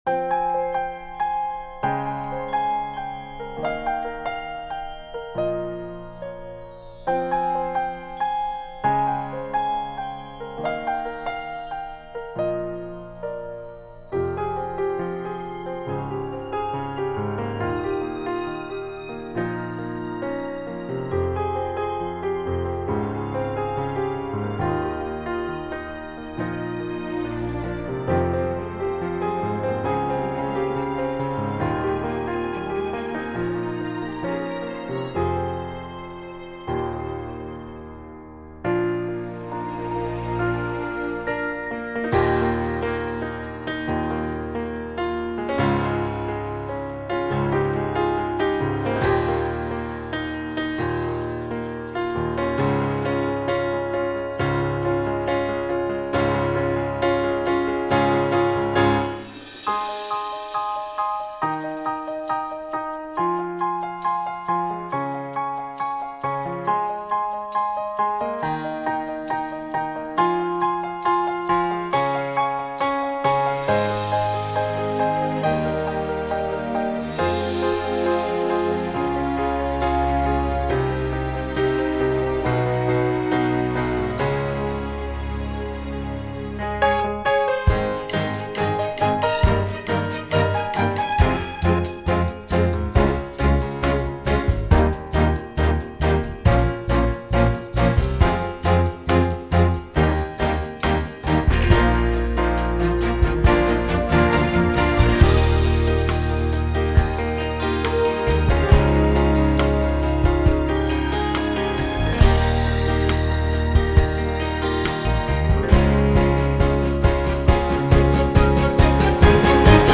караоке
минусовка